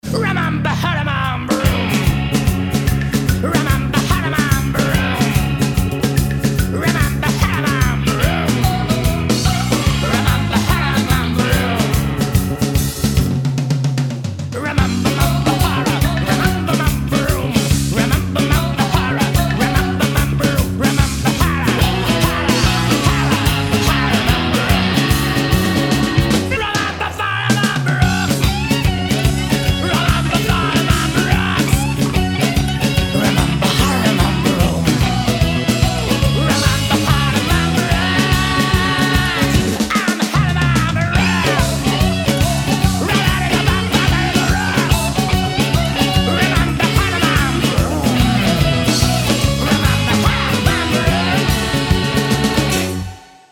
• Качество: 320, Stereo
веселые
смешные